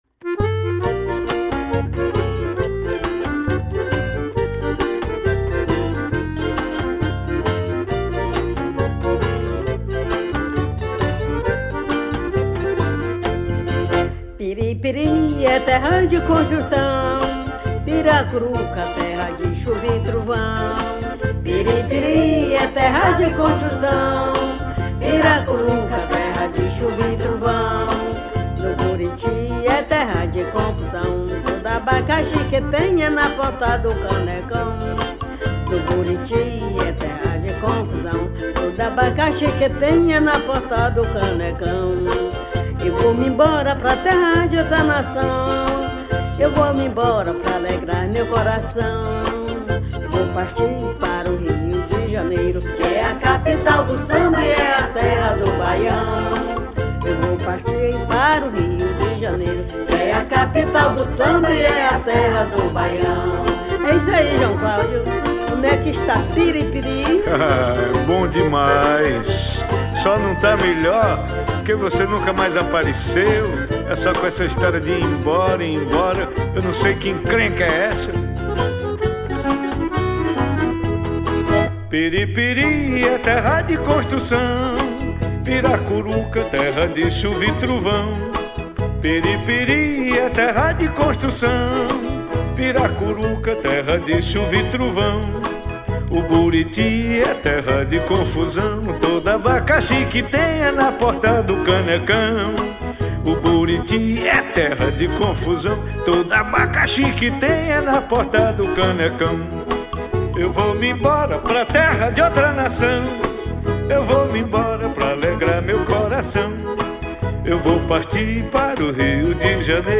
1249   02:59:00   Faixa:     Xote
Acoordeon
Baixo Elétrico 6
Cavaquinho
Percussão